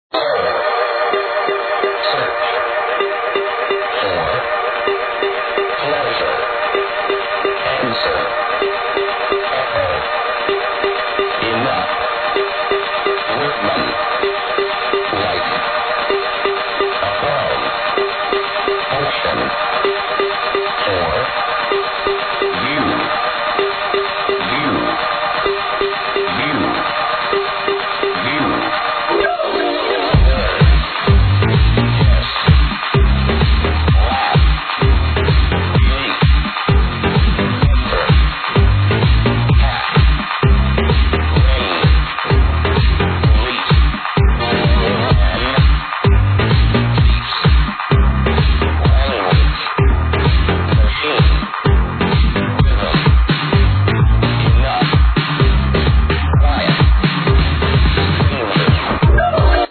The accapella sound familiar.